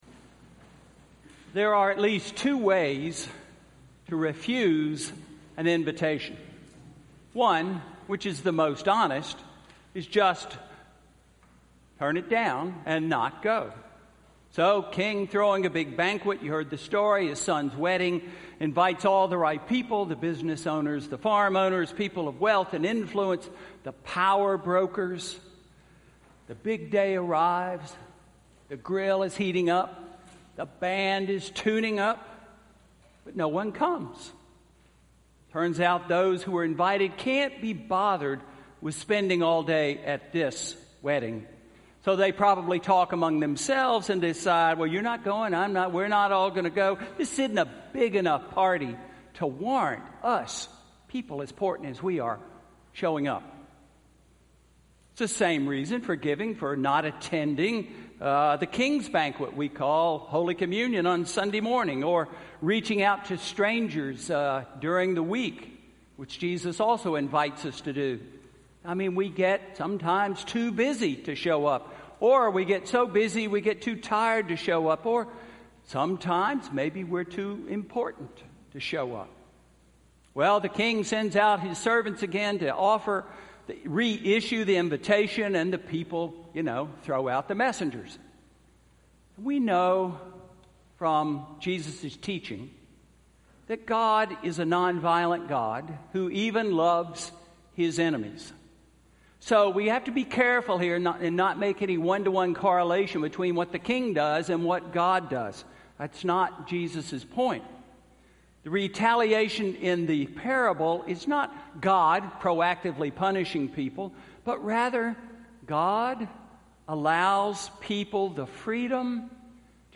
Sermon–October 15, 2017